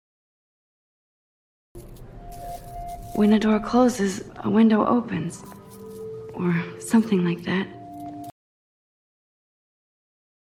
Door close window open